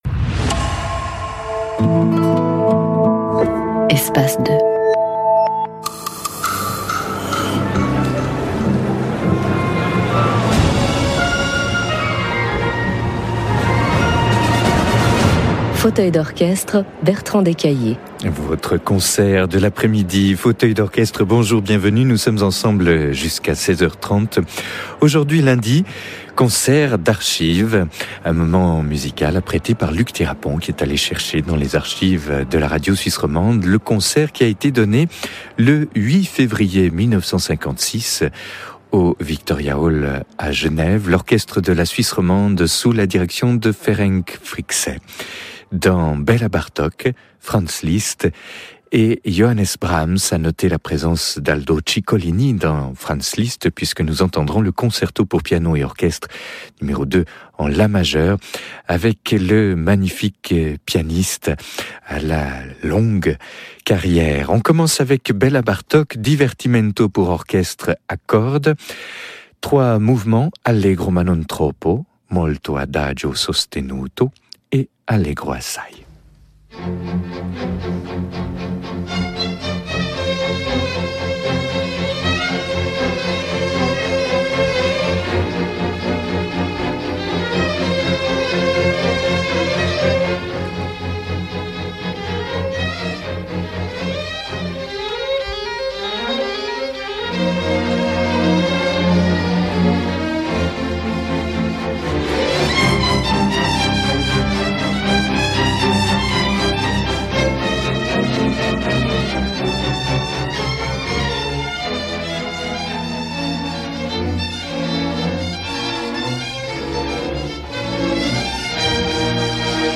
A special concert this week from the archives of Radio Suisse Romande (RSR Espace 2). Recorded on February 8, 1956 and featuring legendary conductor Ferenc Fricsay leading Orchestre de la Suisse Romande in music of Bartok, Liszt and Brahms. Famed Pianist Aldo Ciccolini is featured in the Liszt Piano Concerto Number 2.